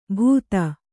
♪ bhūta